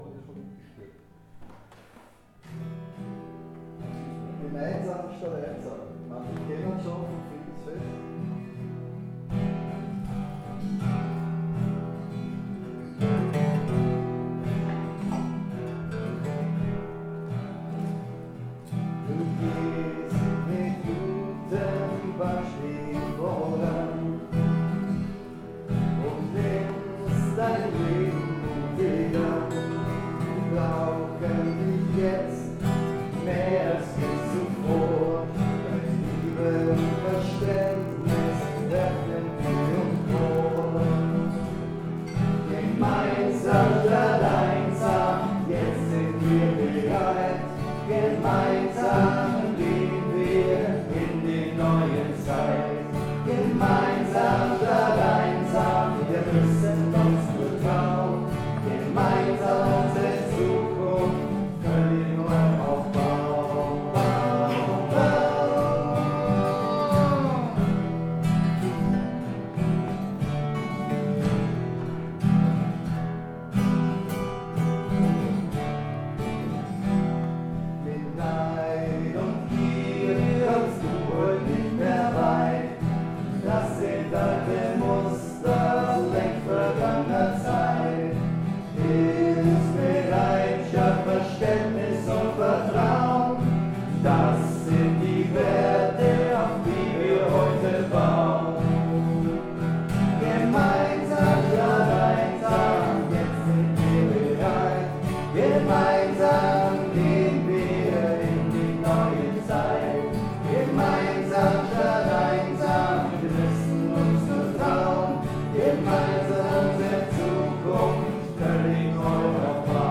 Hier noch ein paar Klangbeiträge vom gestrigen Mitschnitt 😀 ENJOY IT